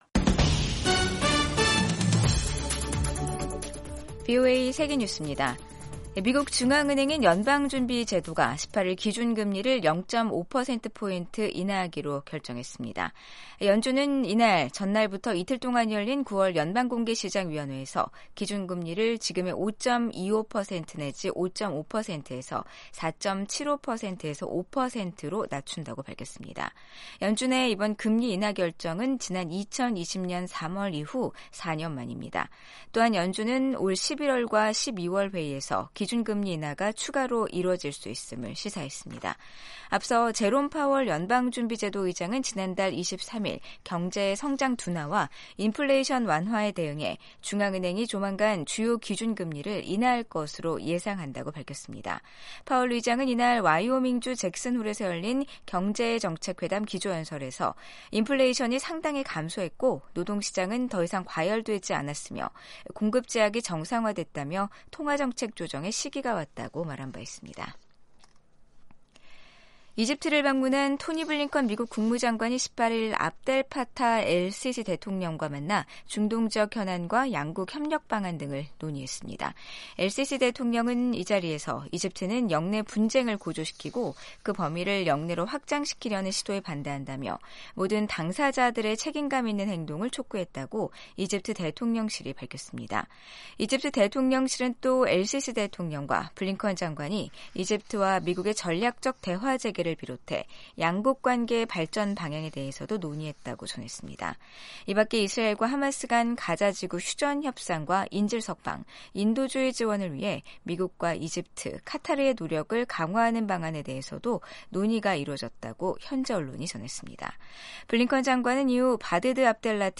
세계 뉴스와 함께 미국의 모든 것을 소개하는 '생방송 여기는 워싱턴입니다', 2024년 9월 19일 아침 방송입니다. 레바논에서 17일 호출기 수백 대가 폭발해 12명이 숨지고 수천 명이 다쳤습니다.